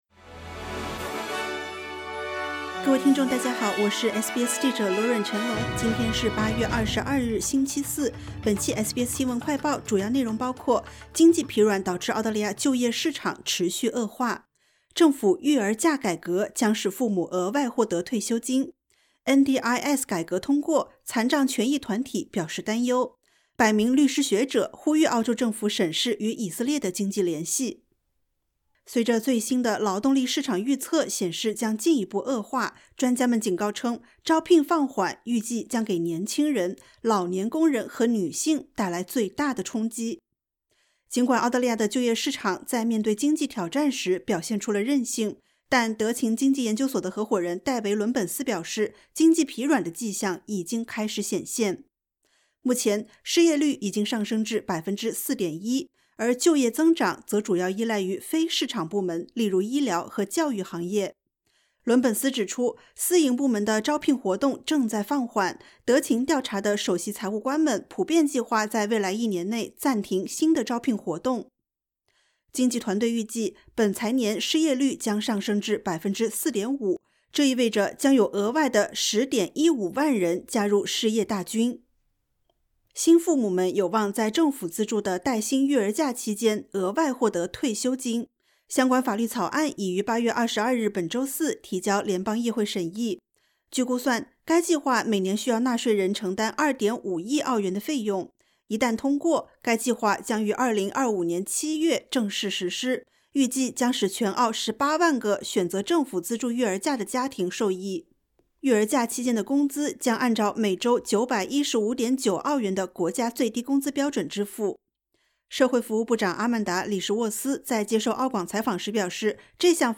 【SBS新闻快报】经济疲软导致澳大利亚就业市场持续恶化